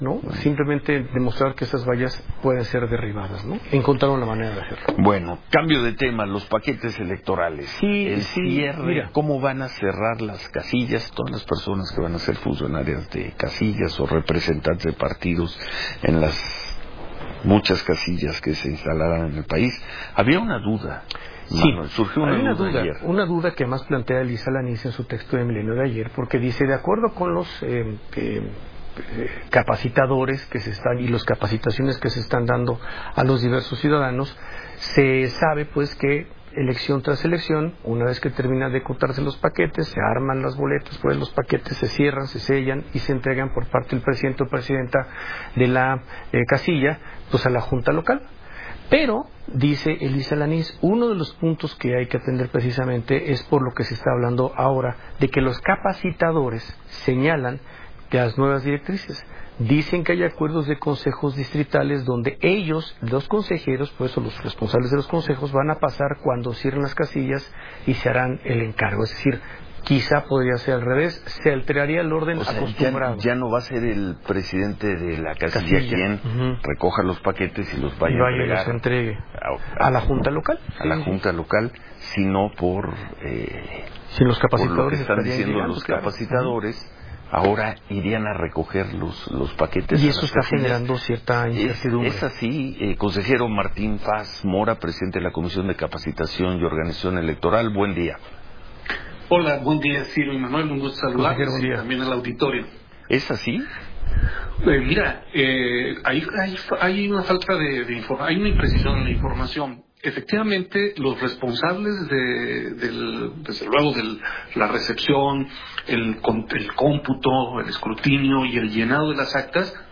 Escucha aquí el audio de la entrevista